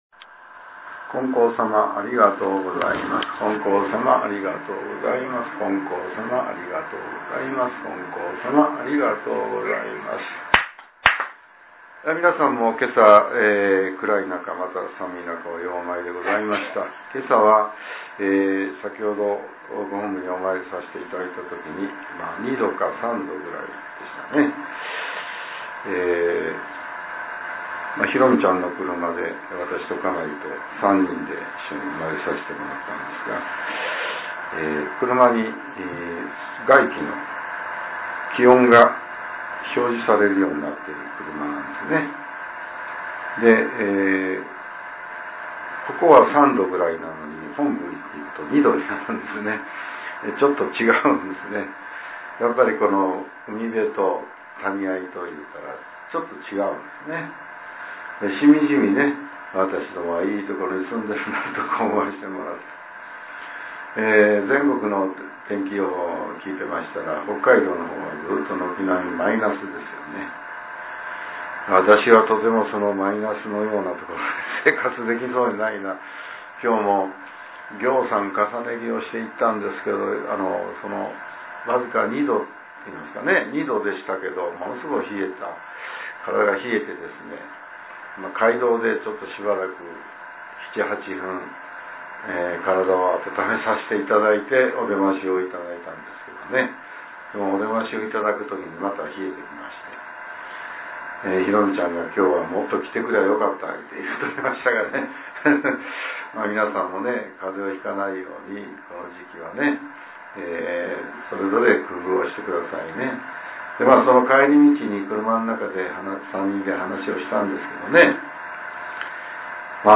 令和６年１２月１５日（朝）のお話が、音声ブログとして更新されています。